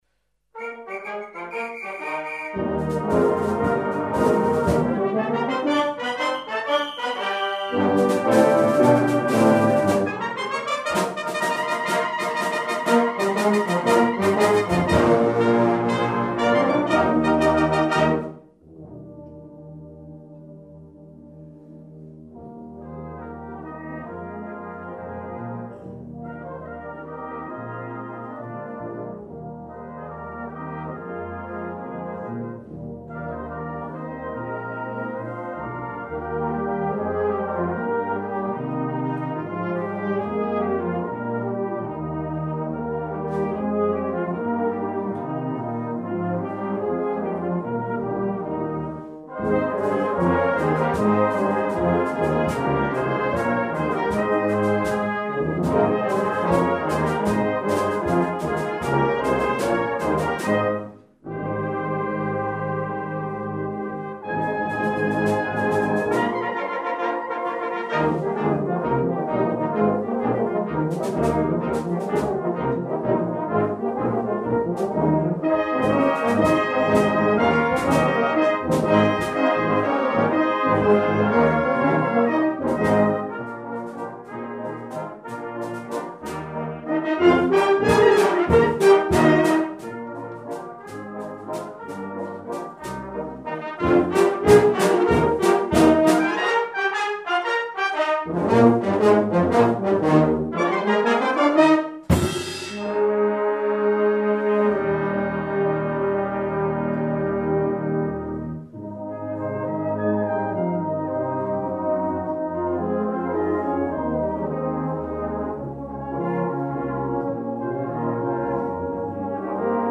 Flugel Horn
Eb Bass